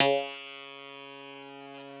genesis_bass_037.wav